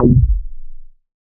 MoogResFilt 007.WAV